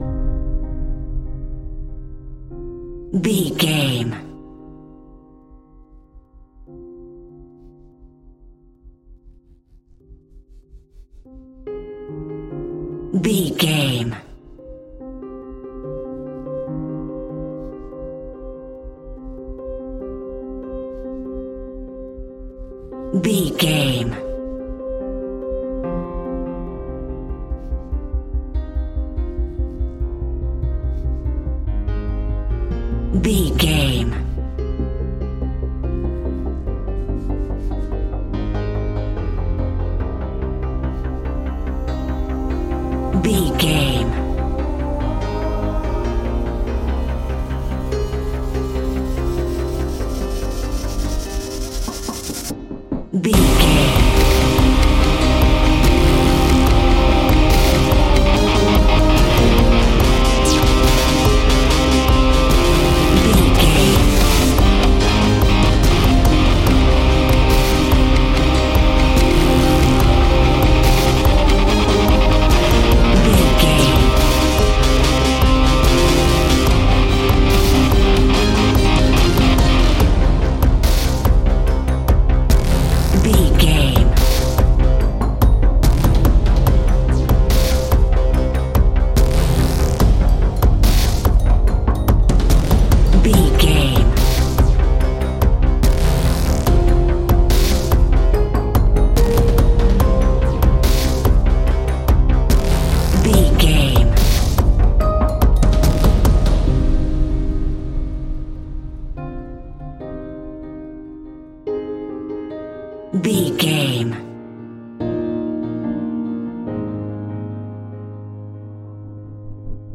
Ionian/Major
E♭
strings
percussion
synthesiser
brass
cello
double bass